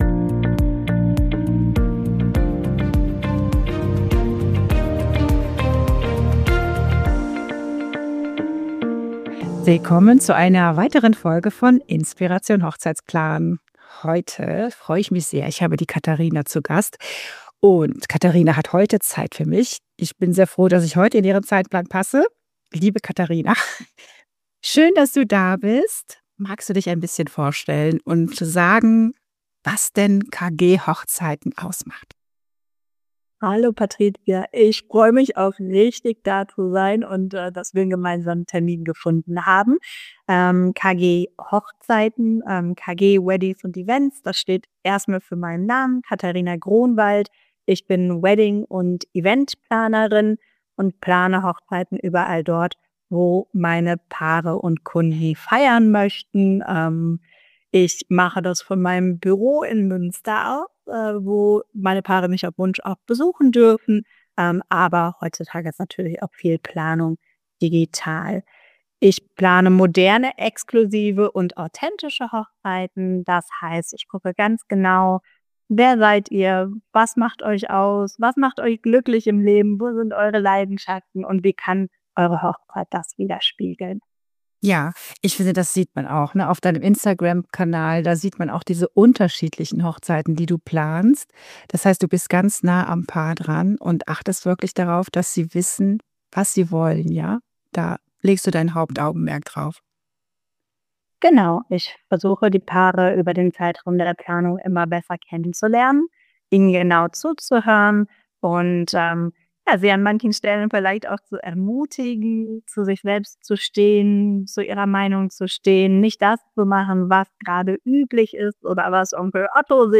In jeder Hochzeitsplanung steckt jede Menge Hochzblut drin! Ein Planerinnen Talk.